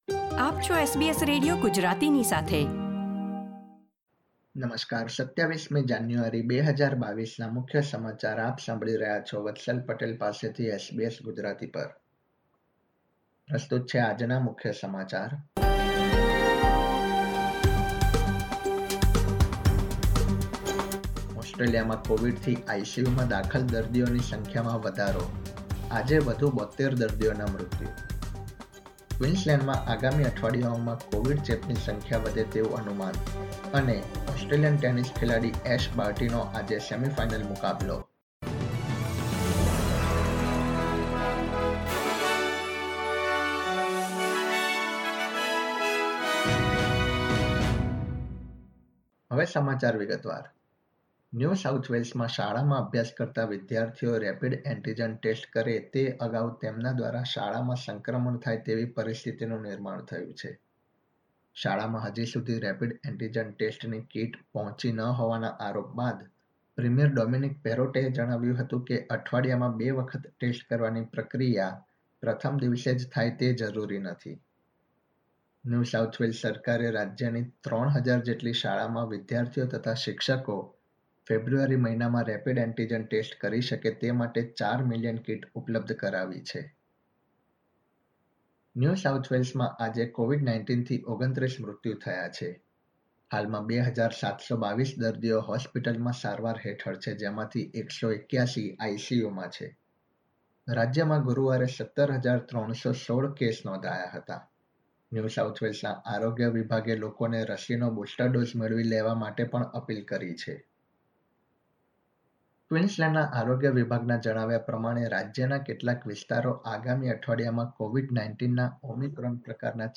SBS Gujarati News Bulletin 27 January 2022